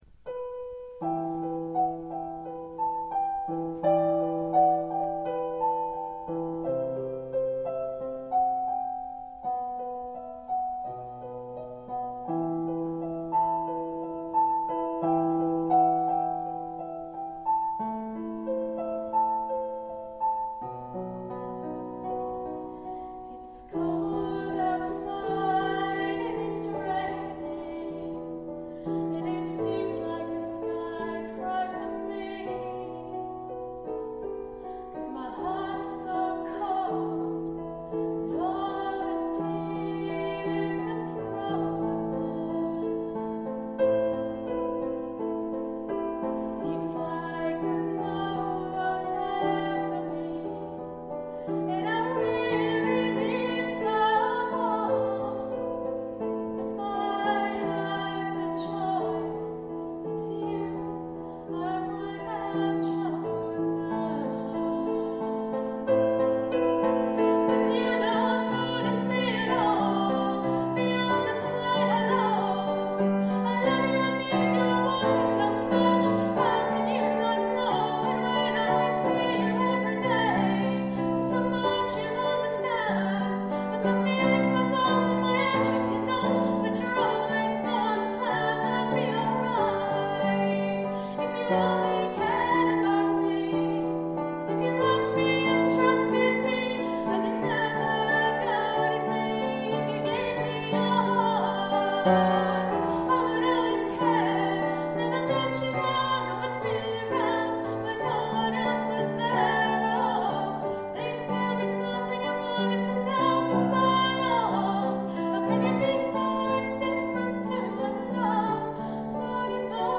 Indifferent - a song I wrote about unrequited love, performed in Autumn 2010